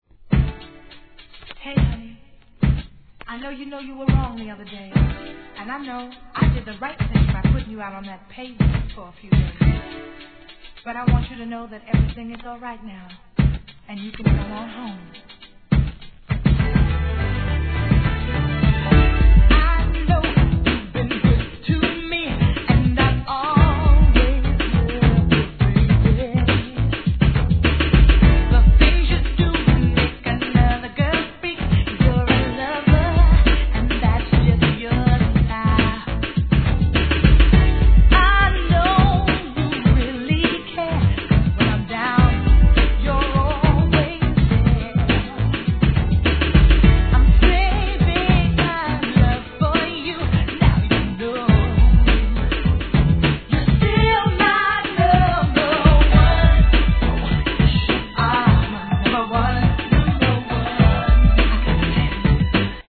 HIP HOP/R&B
素晴らしい歌唱力で聴かせます♪